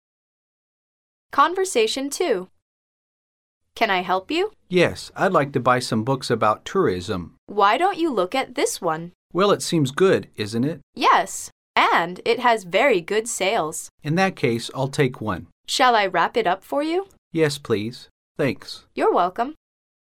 Conversation 2